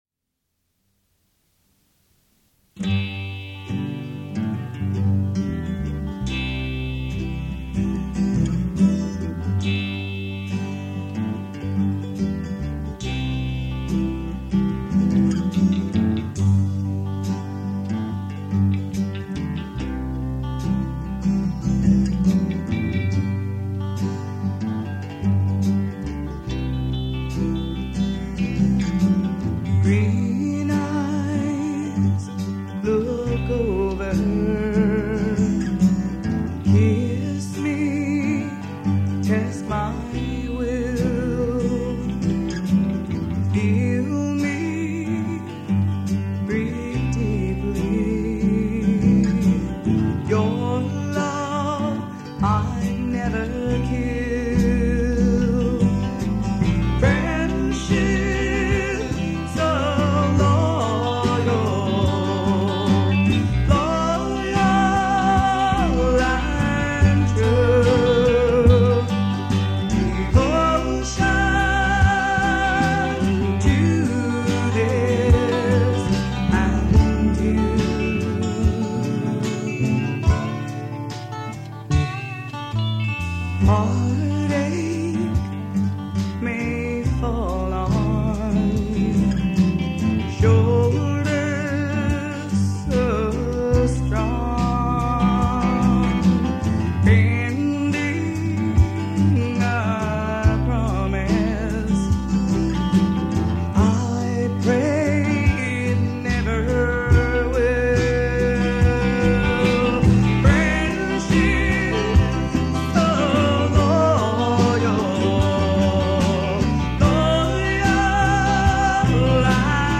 NY-based alt. country group fronted by two female singers.
mandolin
dobro
electric guitar
electric bass
drums